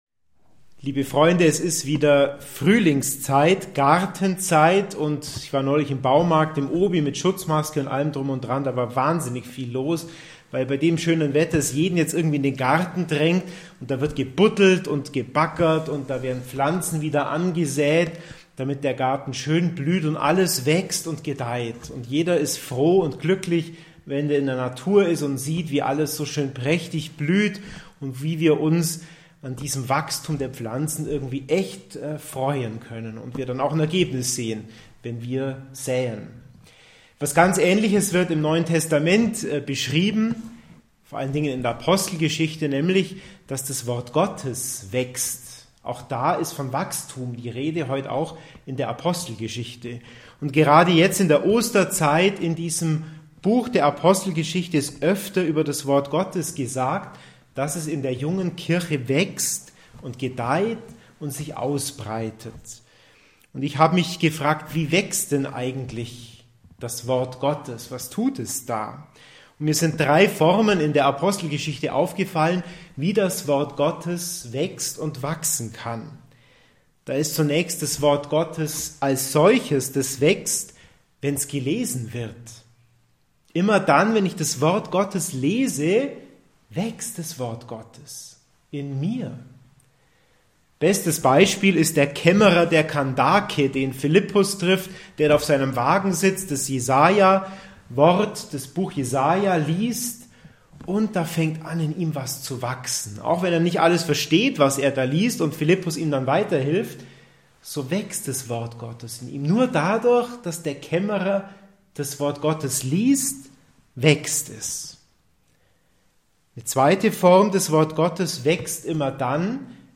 Predigt-Podcast